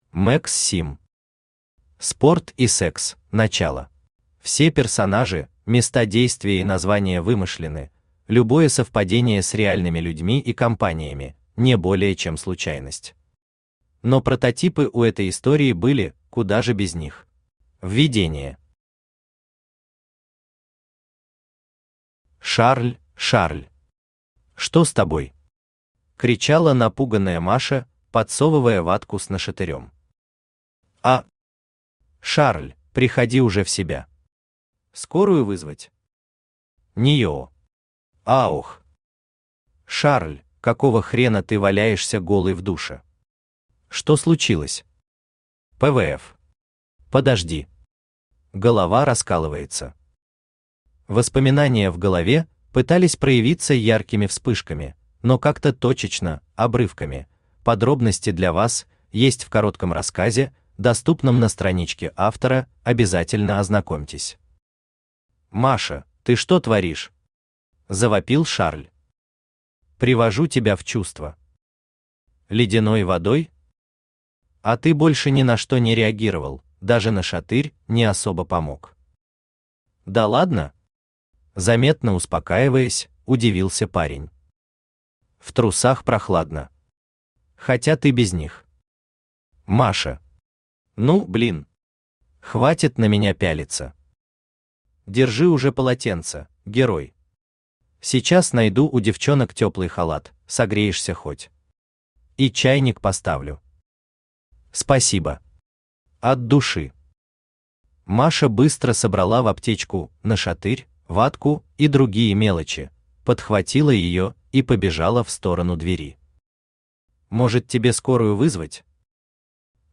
Аудиокнига Спорт и Секс: начало | Библиотека аудиокниг
Aудиокнига Спорт и Секс: начало Автор Max Sim Читает аудиокнигу Авточтец ЛитРес.